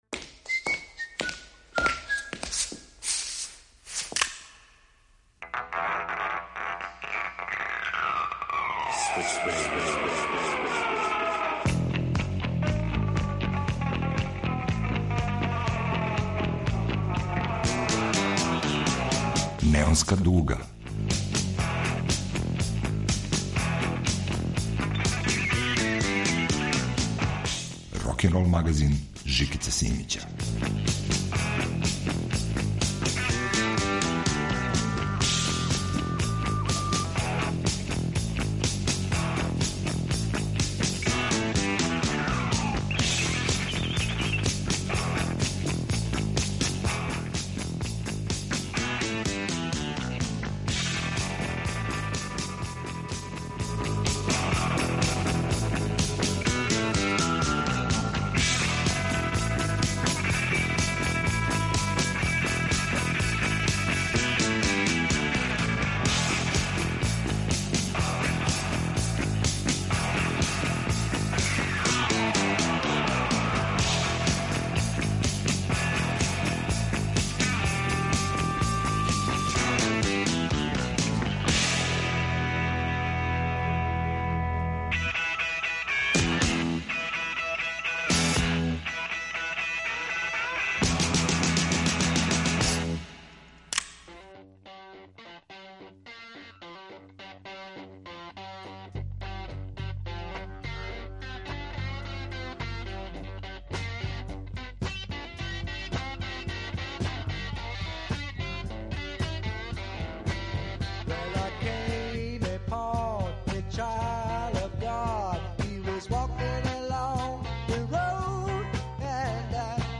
Велики је то лук ‒ слушаћете двосатни „трип" кроз прошлост, садашњост и будућност рок музике.